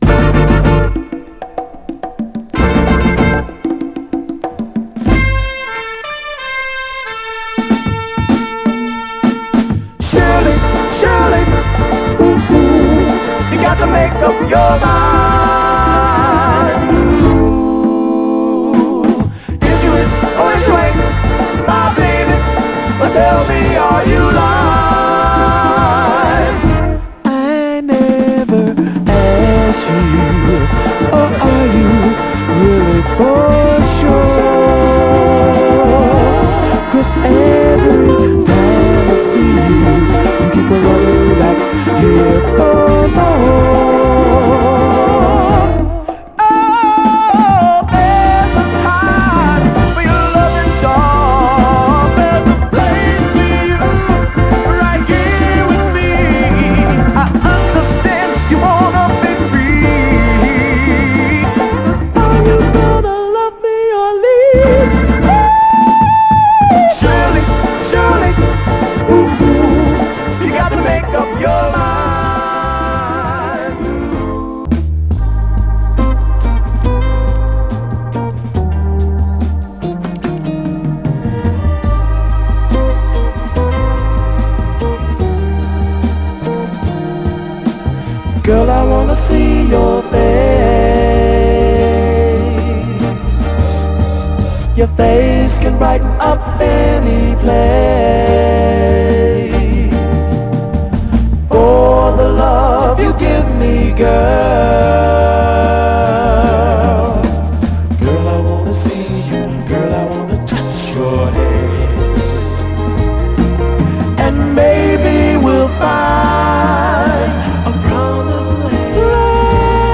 クリーヴランド発のソウル・グループ
※試聴音源は実際にお送りする商品から録音したものです※